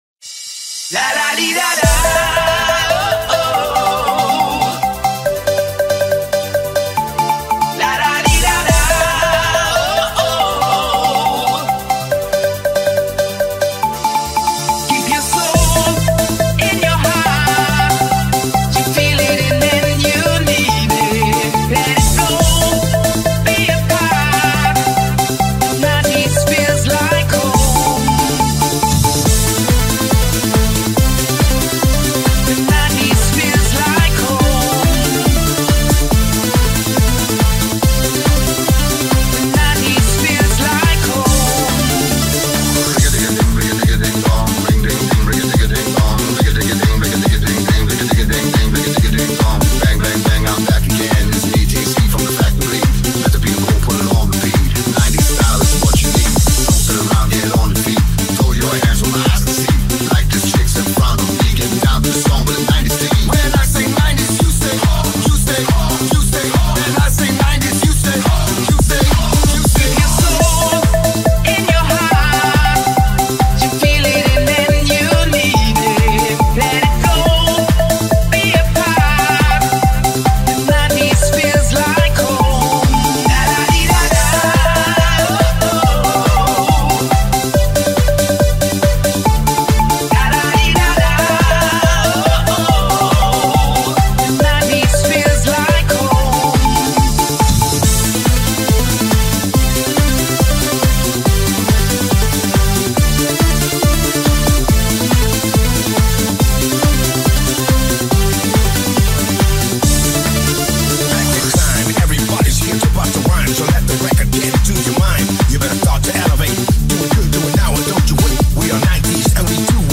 Eurodance Music Mix
Eurodance-Music-Mix.mp3